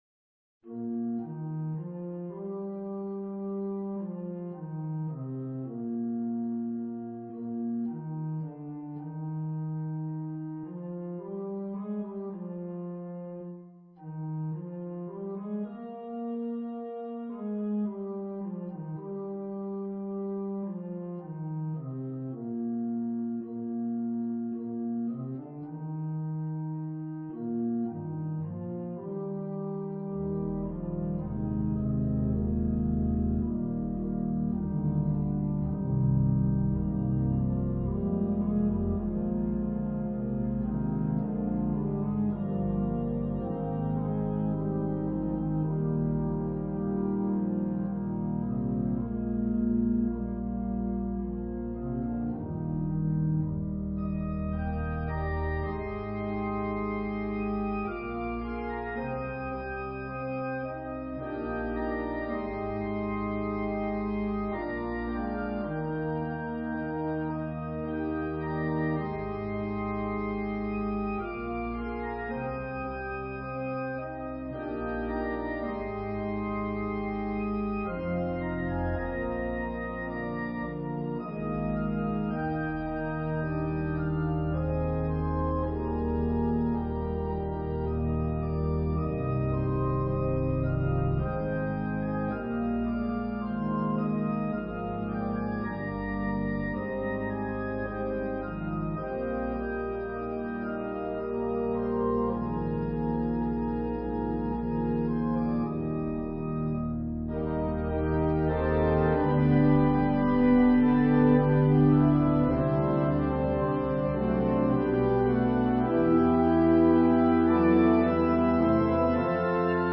organ solo arrangement
Voicing/Instrumentation: Organ/Organ Accompaniment